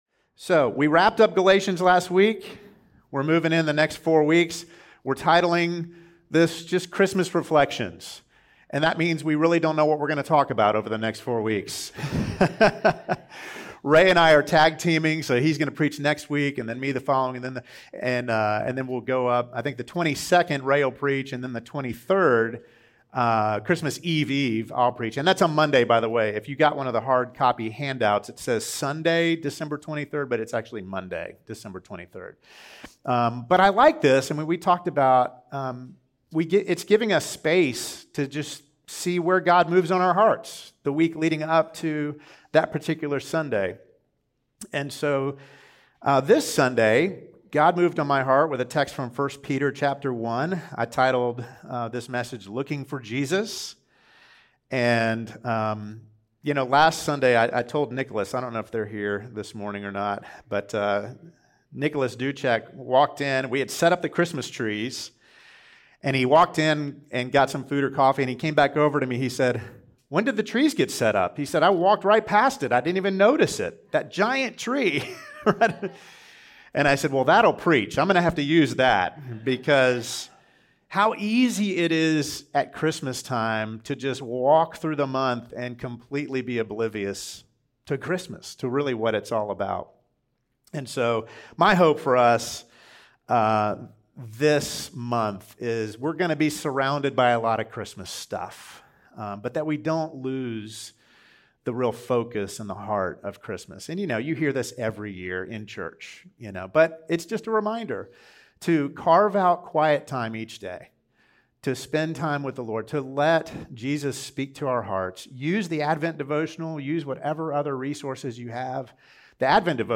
Sermons | Good News Church Georgia